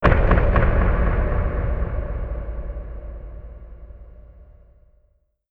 Jumpscare_06.wav